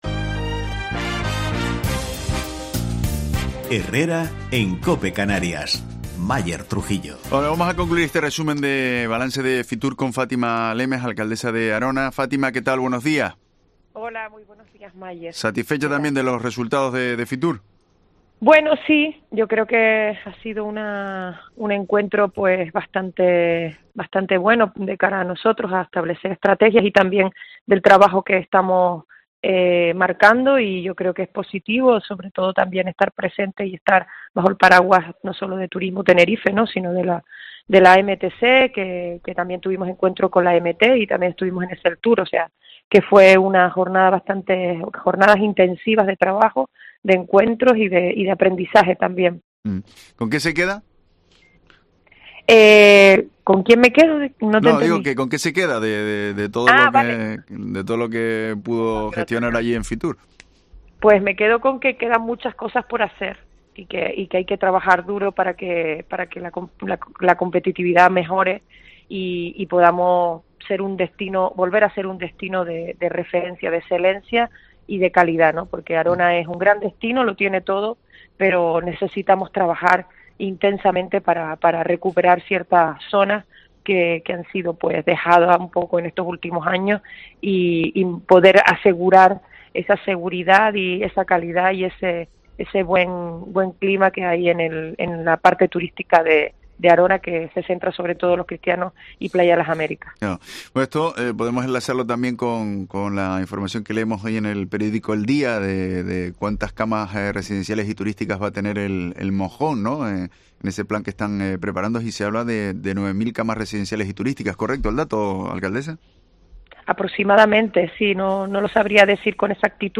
Entrevista a Fátima Lemes, alcaldesa de Arona, balance FITUR